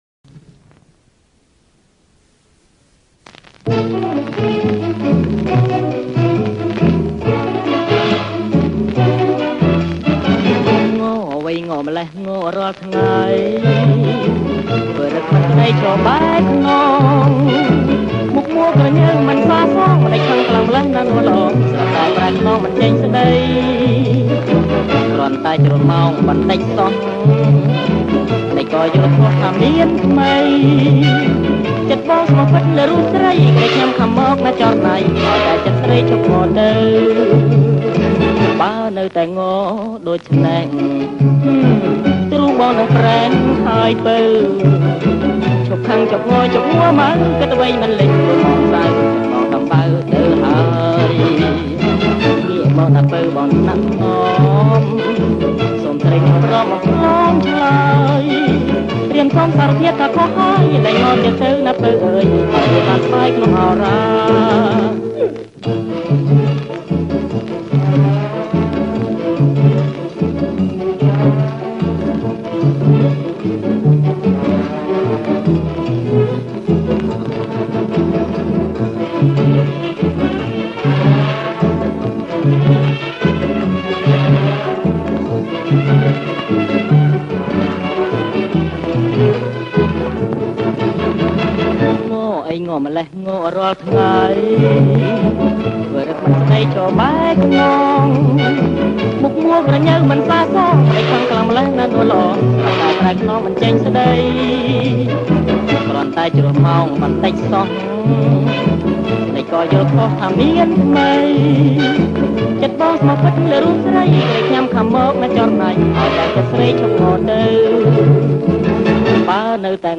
• ប្រគំជាចង្វាក់ Cha Cha Cha
ប្រគំជាចង្វាក់ Cha Cha Cha